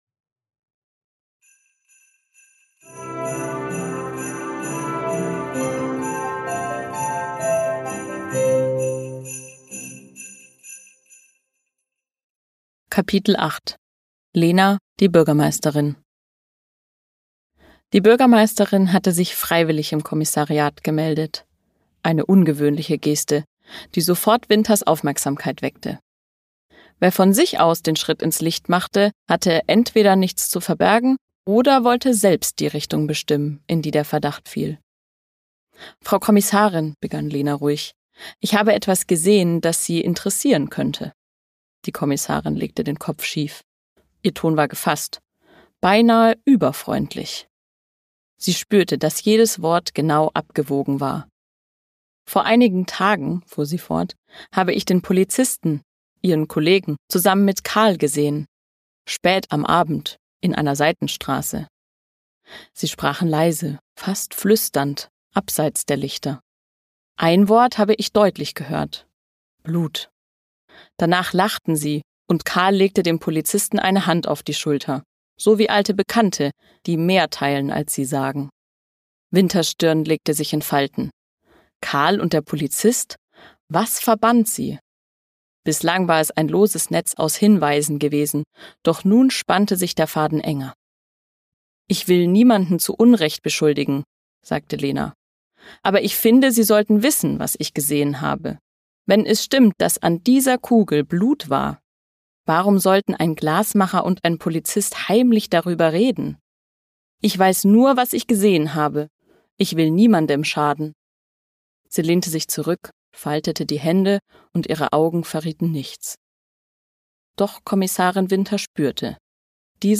Lass dich von acht verzaubernden Stimmen in die